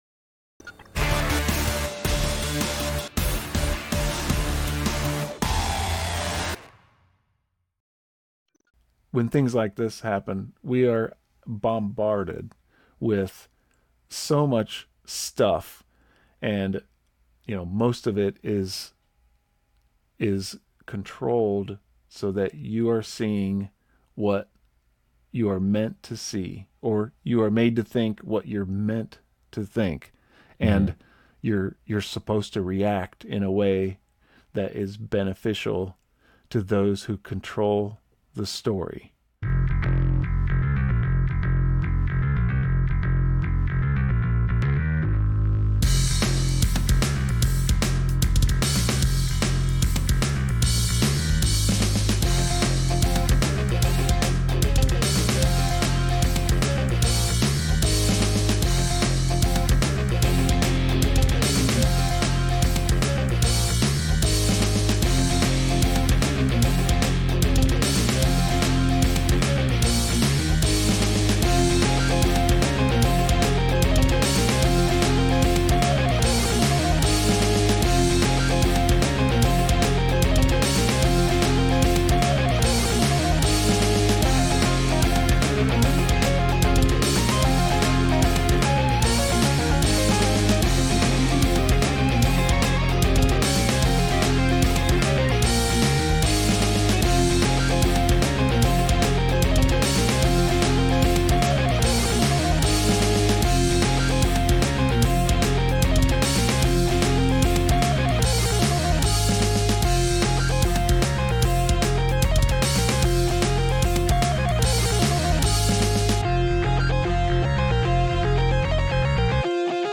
We will discuss any topic and are not afraid to challenge anything to uncover deception. This podcast is just a couple regular guys who love freedom and truth and are on a journey in pursuit of both.